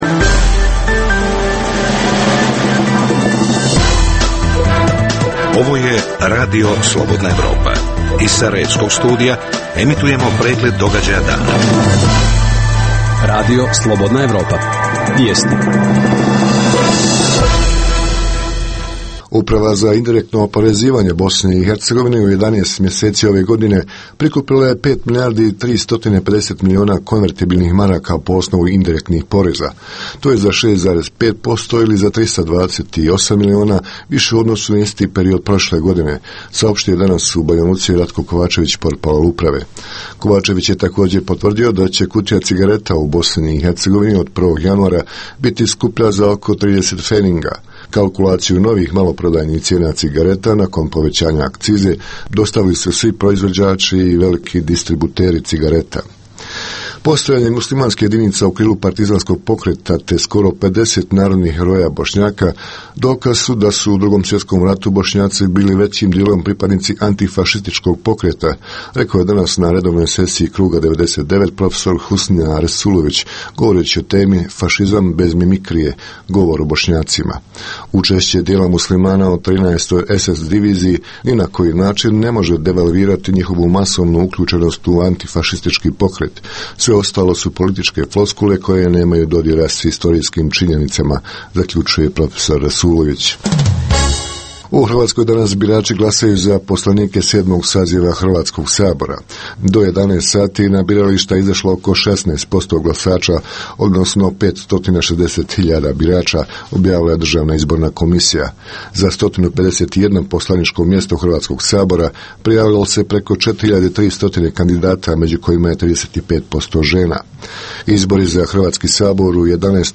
- Intervju: Rumunski ambasador u BiH Filip Teodorescu.